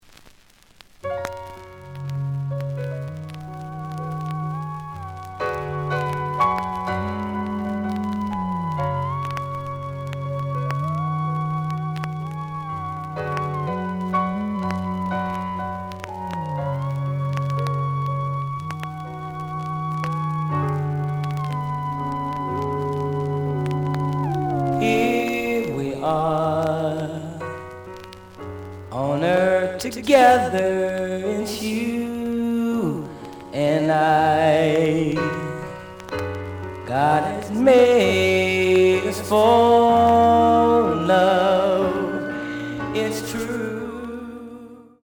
The audio sample is recorded from the actual item.
●Format: 7 inch
●Genre: Funk, 70's Funk
Looks good, but slight noise on both sides.)